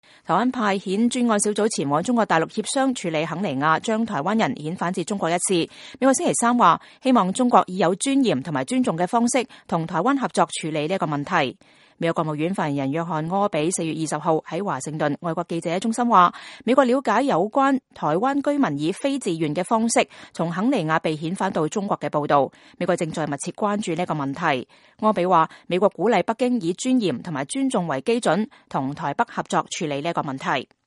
美國國務院發言人約翰•柯比
美國國務院發言人約翰•柯比4月20日在華盛頓外國記者中心說：“我們了解有關台灣居民以非自願的方式從肯尼亞被遣返到中國的報道。